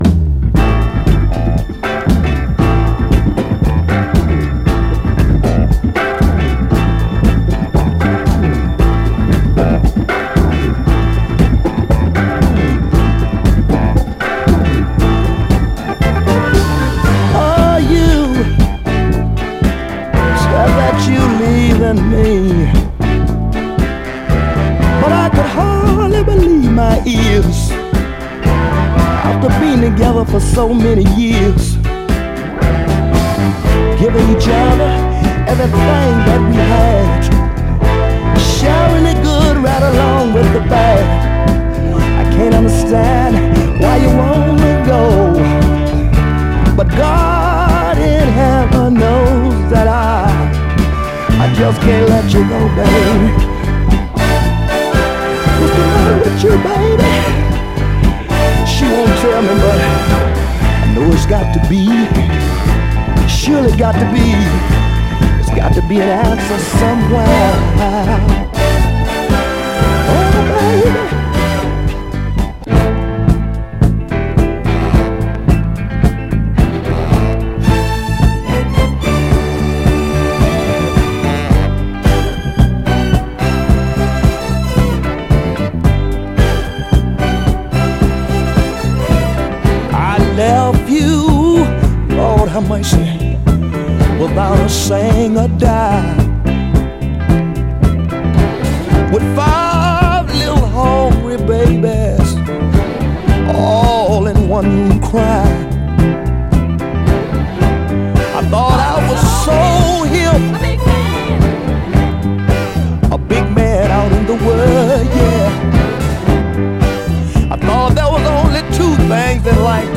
ストリングスが効いた軽快ノーザン・ダンサー
※試聴音源は実際にお送りする商品から録音したものです※